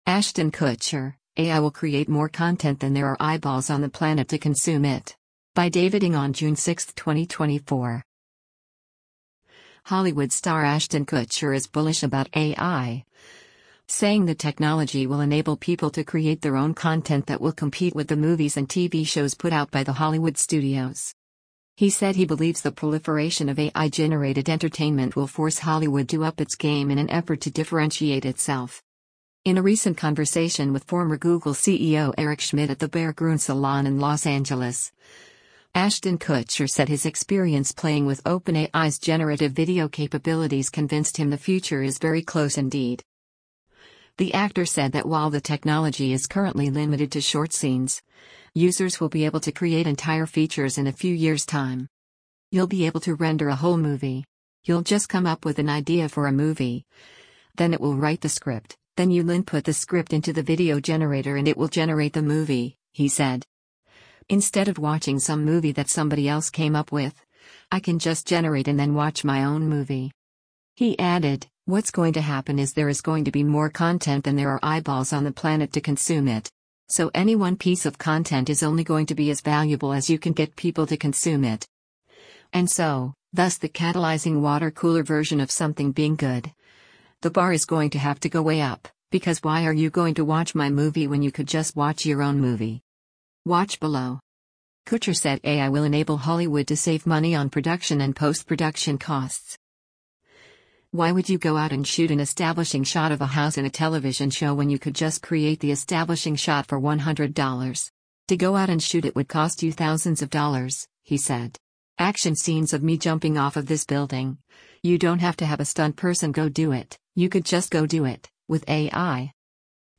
In a recent conversation with former Google CEO Eric Schmidt at the Berggruen Salon in Los Angeles, Ashton Kutcher said his experience playing with OpenAI’s generative video capabilities convinced him the future is very close indeed.